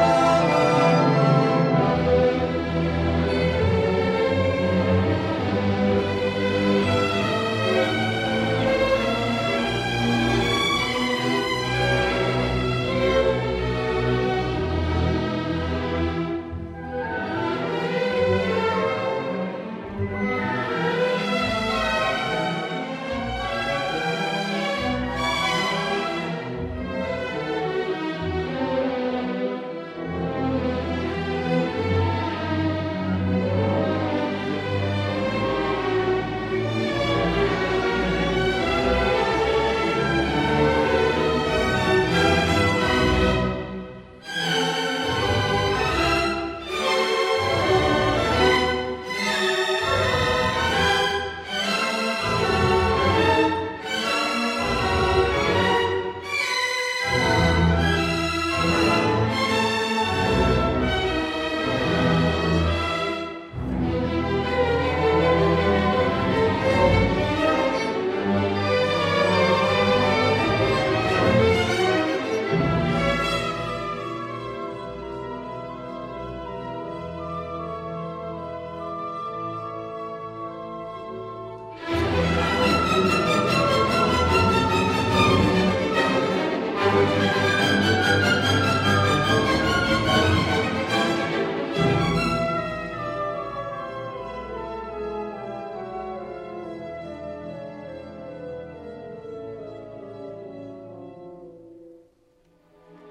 Yevgeni Mravinsky: St. Petersburg Philharmonic, 2006 Quarter note = 104, Quarter note =76 after [C]